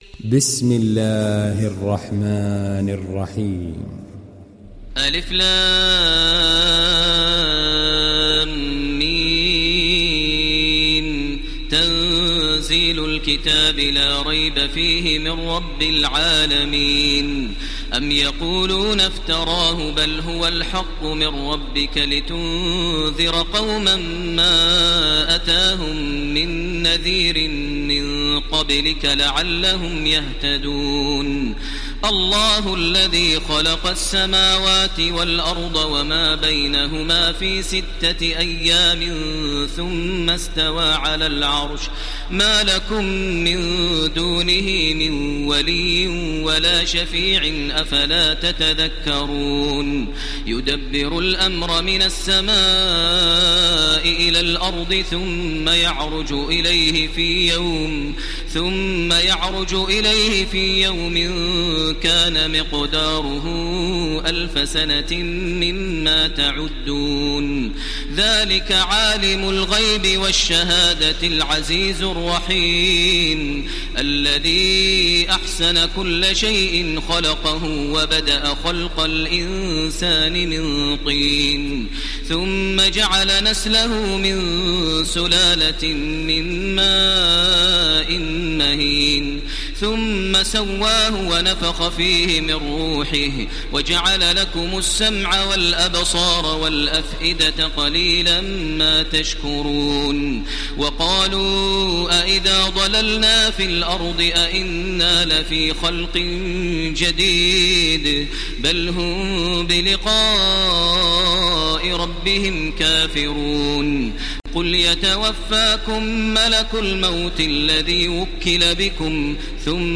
Download Surat As Sajdah Taraweeh Makkah 1430